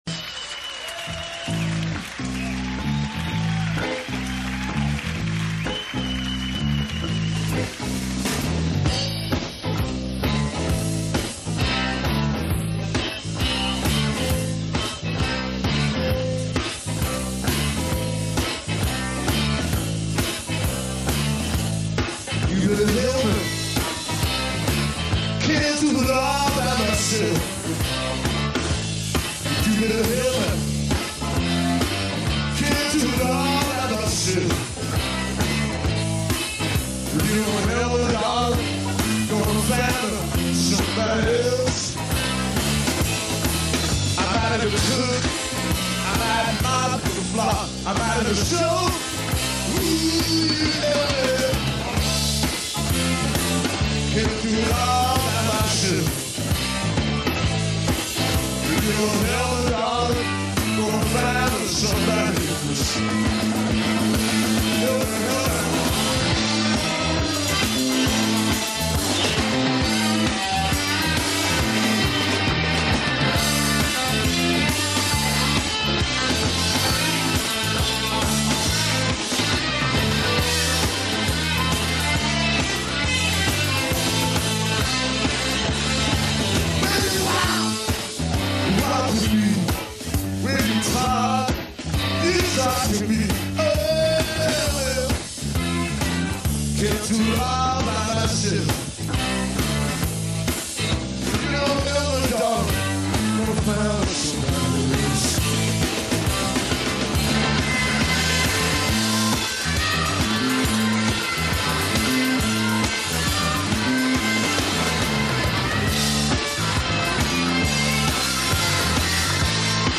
guesting on lead guitar